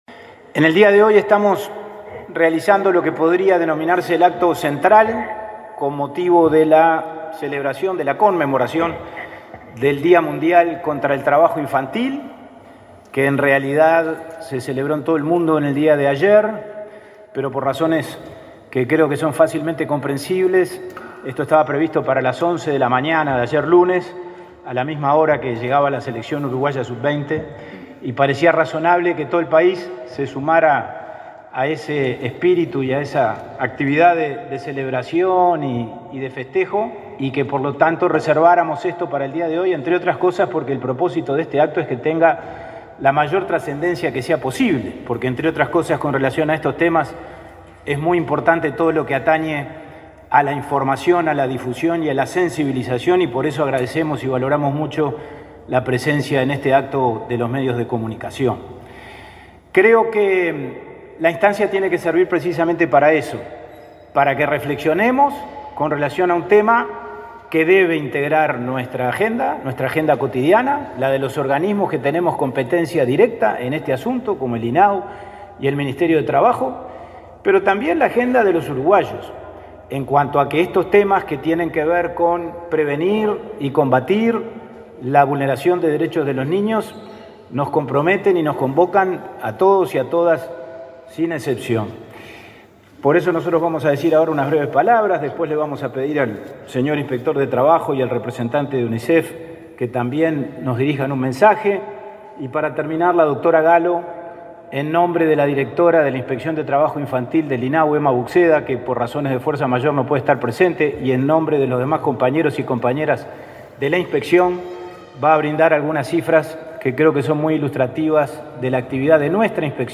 Palabras de autoridades del INAU 13/06/2023 Compartir Facebook X Copiar enlace WhatsApp LinkedIn El presidente del Instituto del Niño y el Adolescente del Uruguay (INAU), Pablo Abdala, y la directora general del organismo, Dinorah Gallo, realizaron una conferencia de prensa este martes 13, en el marco del Día Mundial contra el Trabajo Infantil.